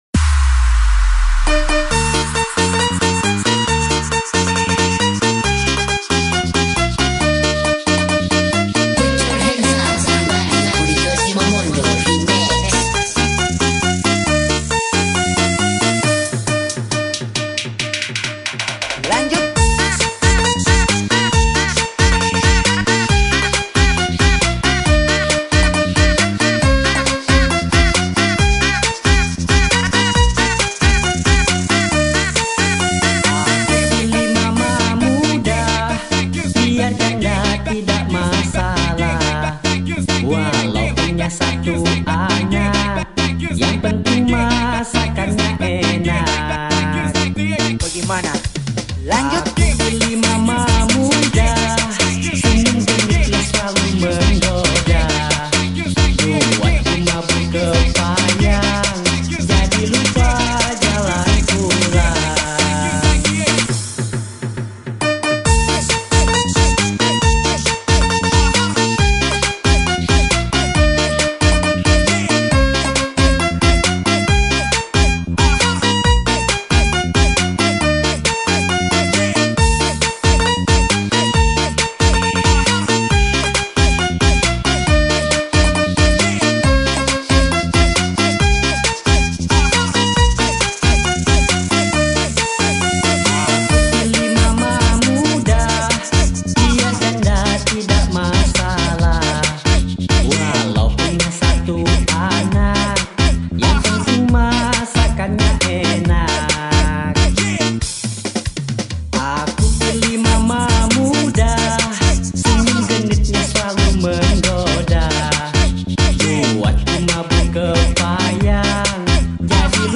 dj remix
house dan remix